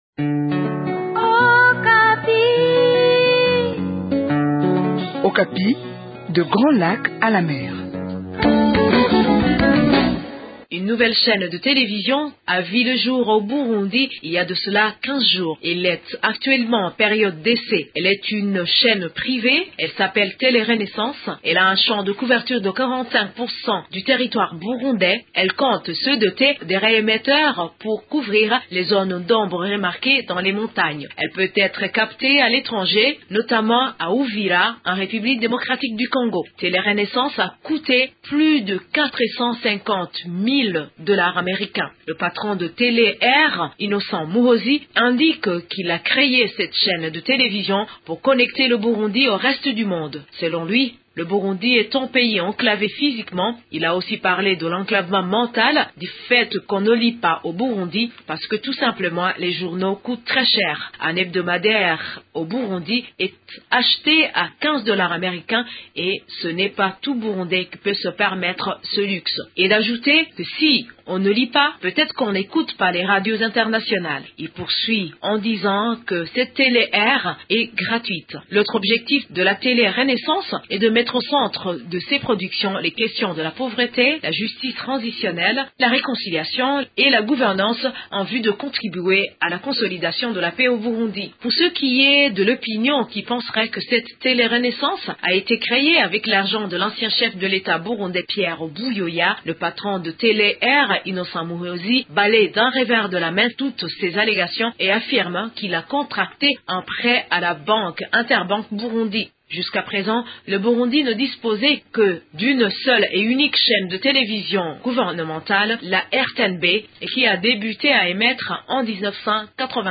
Les détails avec notre correspondante sur place au Burundi